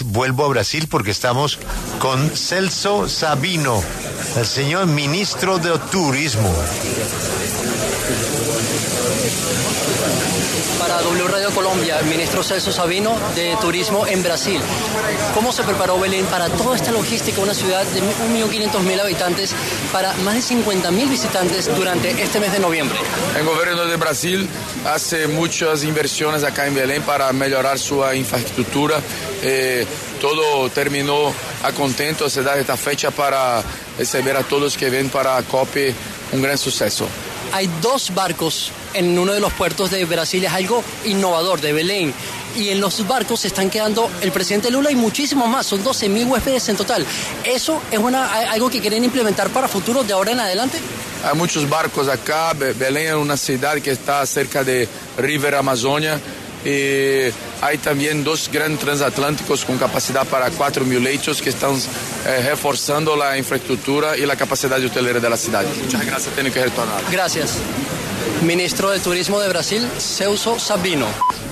La W dialogó con Celso Sabino, ministro de Turismo de Brasil, acerca de la preparación de tuvo Belém, una ciudad de 1.5 millones de habitantes, para acoger uno de los eventos internacionales más importantes del mundo, la COP30.